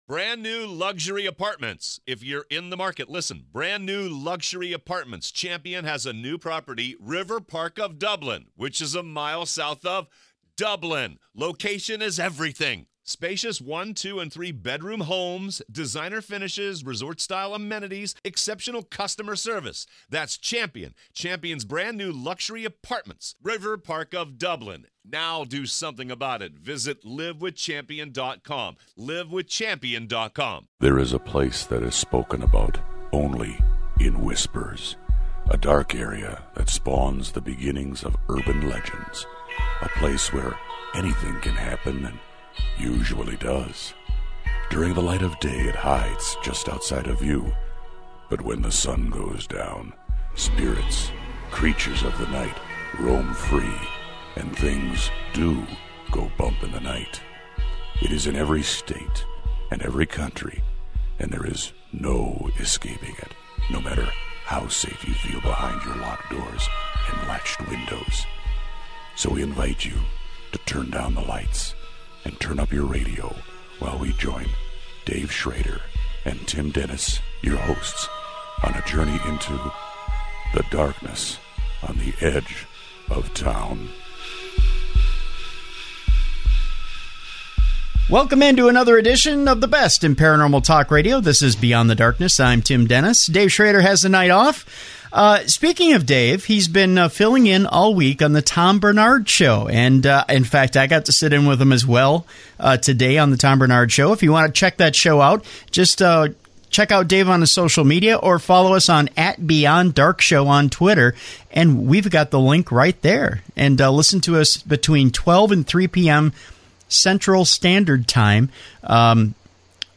Welcome into another edition of the best in paranormal talk radio.